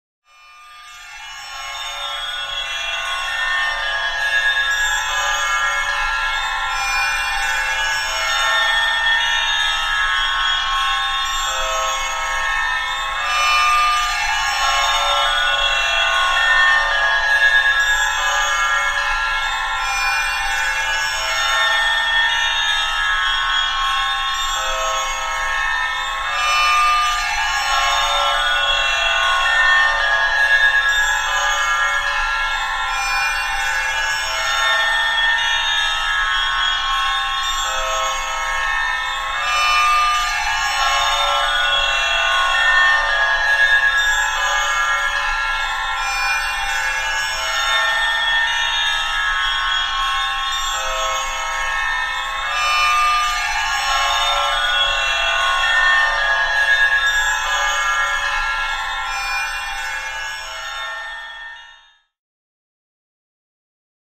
Звук рассеивания кислотного запаха для театра